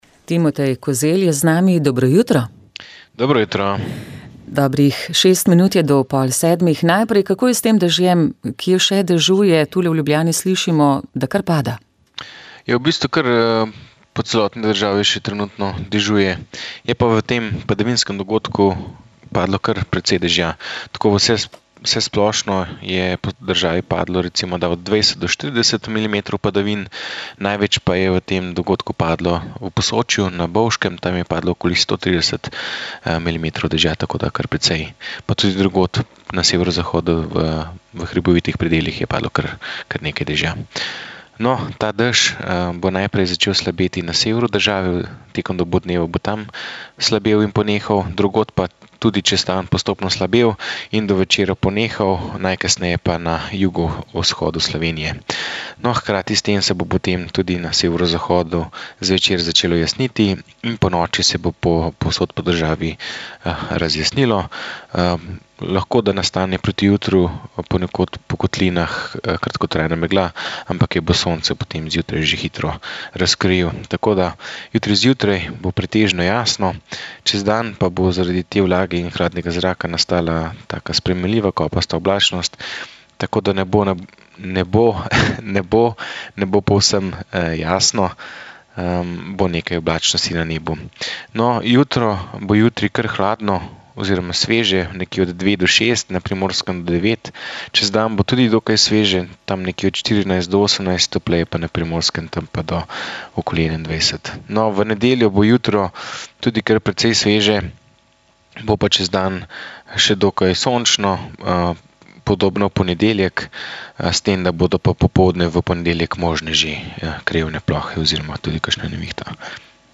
O tem, kaj prinaša zakon o enakosti, smo se pogovarjali z moralnim teologom